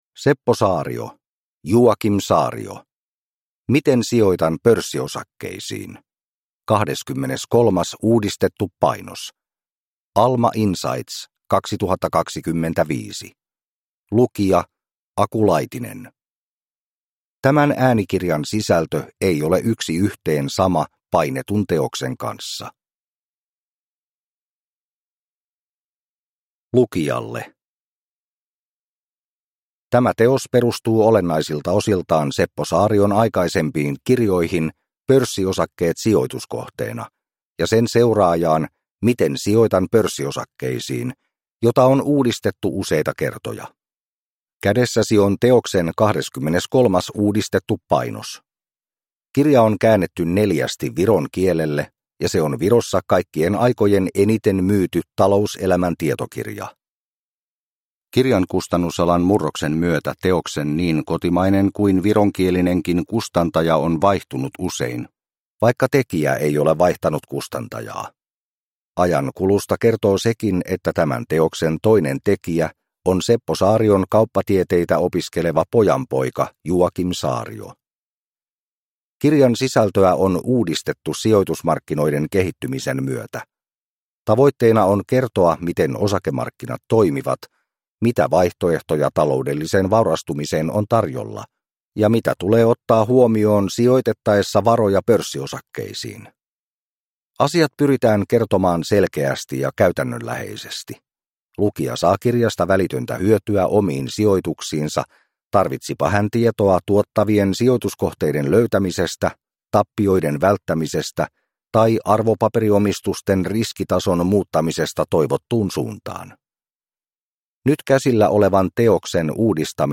Miten sijoitan pörssiosakkeisiin – Ljudbok